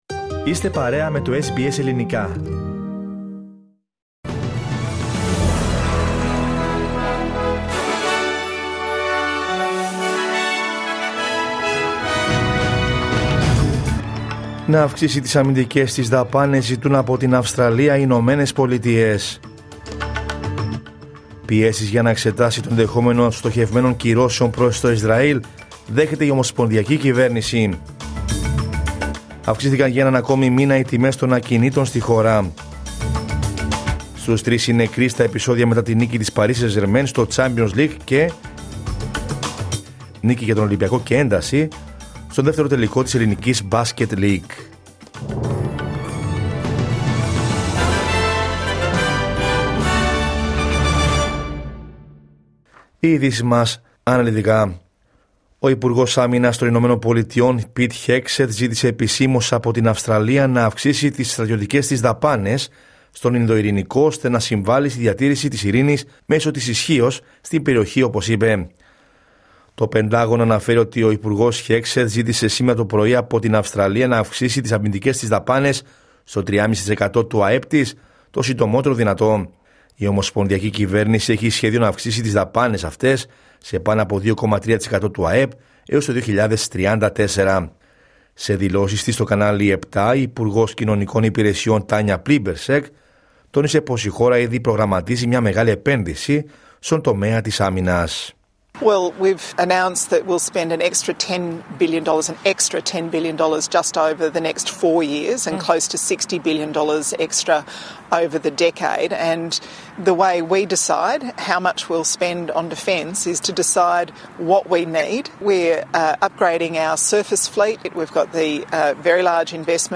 Ειδήσεις από την Αυστραλία, την Ελλάδα, την Κύπρο και τον κόσμο στο Δελτίο Ειδήσεων της Δευτέρας 2 Ιουνίου 2025.